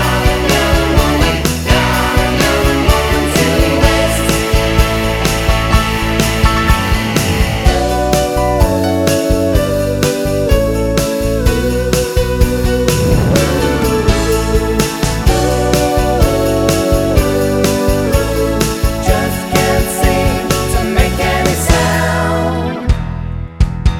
No Intro Rock 4:21 Buy £1.50